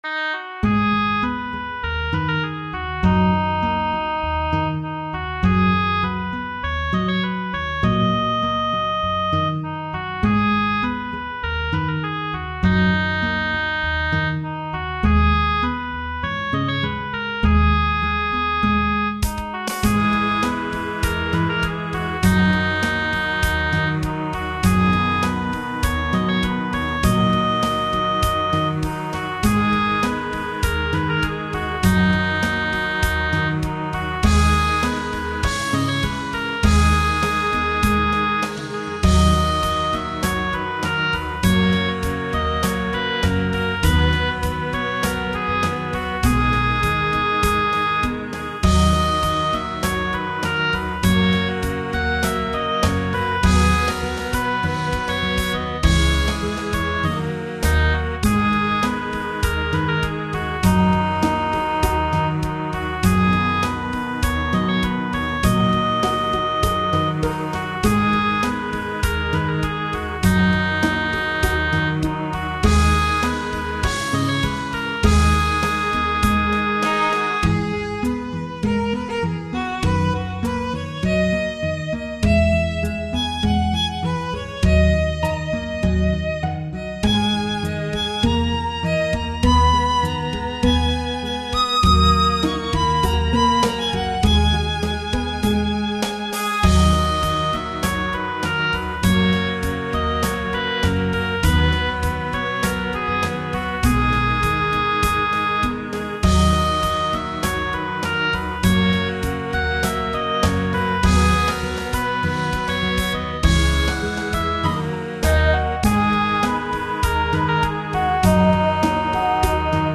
テンポがかなり速めです。
ピアノも入れてみましたが、ちゃんと聴こえるでしょうか……。